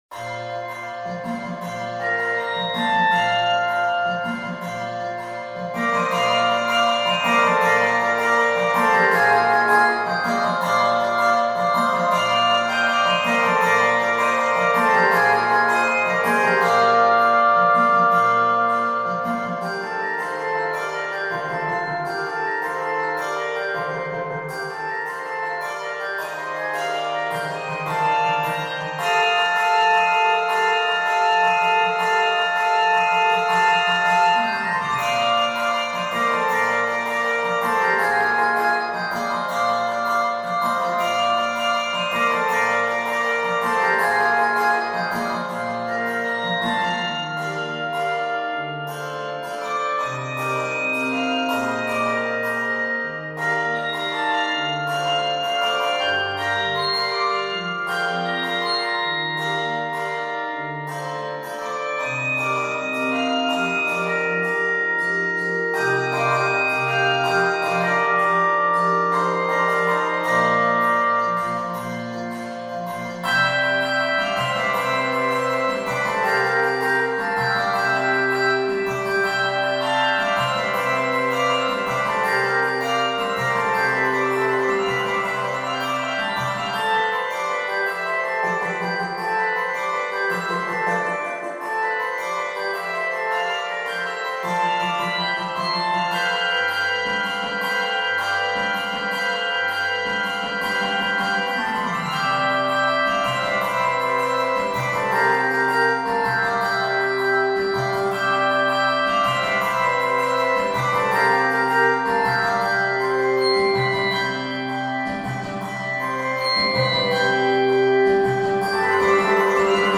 Keys of Bb Major and C Major.